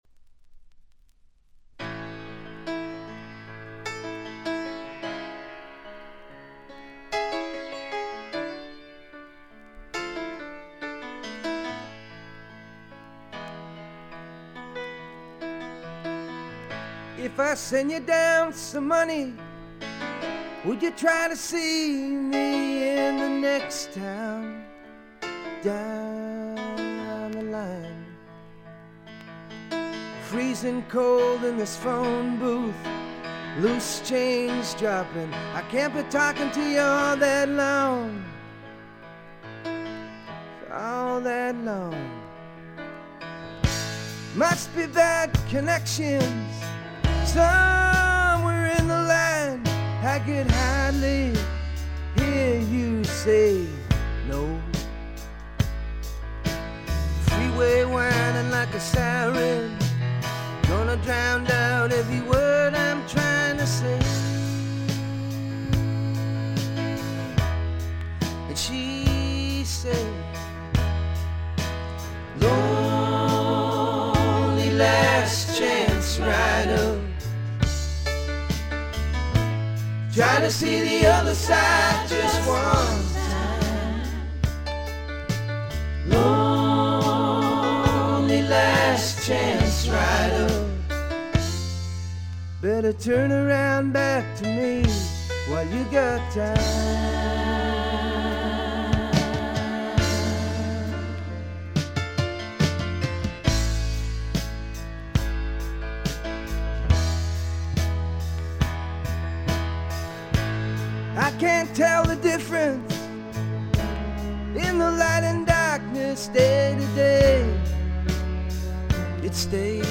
主に静音部で軽微なチリプチ（A3序盤では目立ちます）。
ボブ・ディランのフォロワー的な味わい深い渋い歌声はそのままに、むしろ純度がより上がった感があります。
試聴曲は現品からの取り込み音源です。